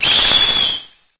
Cri_0881_EB.ogg